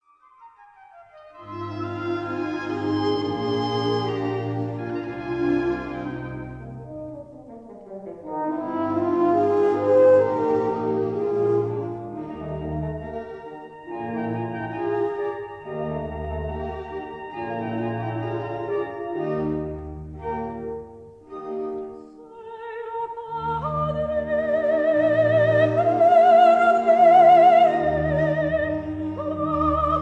Recorded in Abbey Road Studio No. 1, London